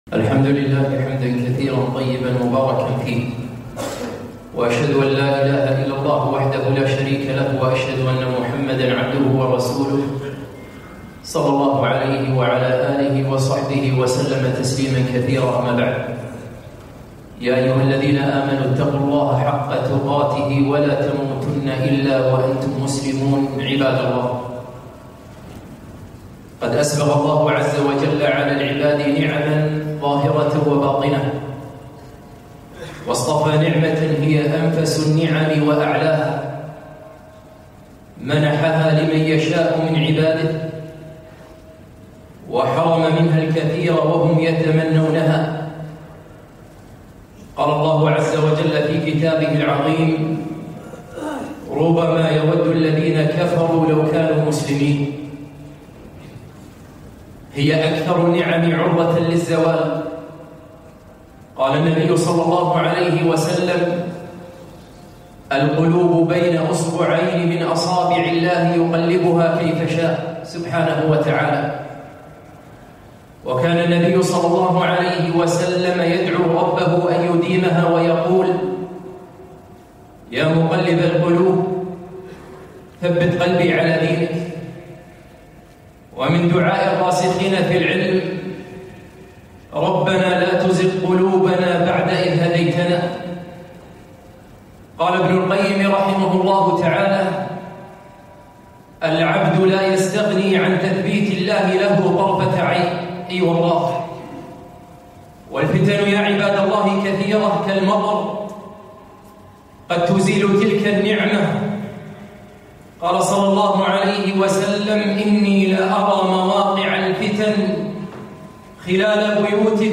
خطبة - ربنا لا تزغ قلوبنا بعد إذ هديتنا